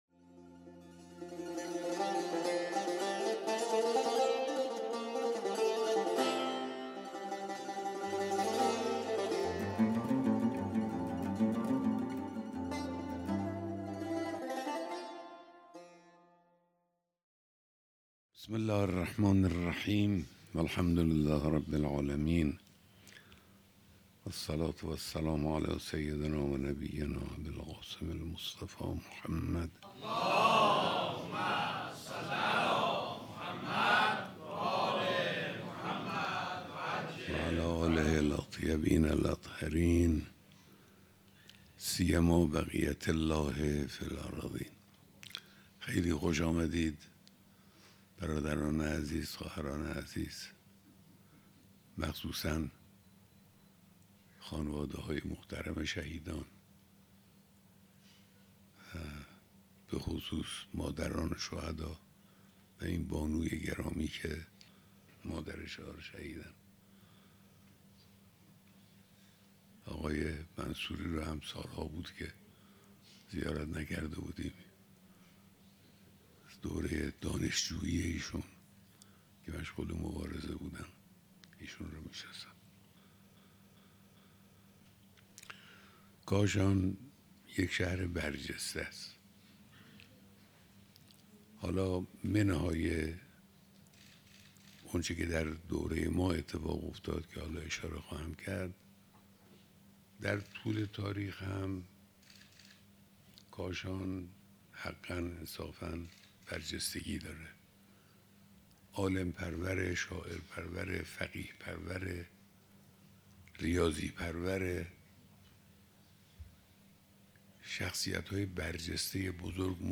بیانات در دیدار دست اندرکاران کنگره ملّی شهدای کاشان